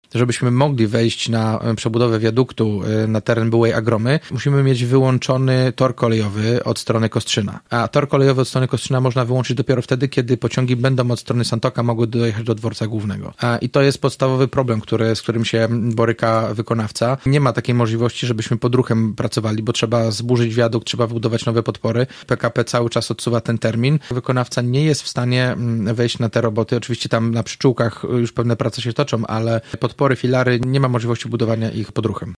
Nie we wrześniu, a w grudniu ma się skończyć przebudowa ul. Kostrzyńskiej –przyznał dziś w Radiu Gorzów prezydent Jacek Wójcicki.